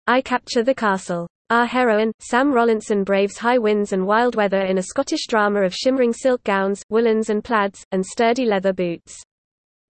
Text to Speech for Accessibility and Learning
Voicepods can extract text from images and convert it to audio.